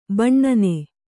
♪ baṇṇane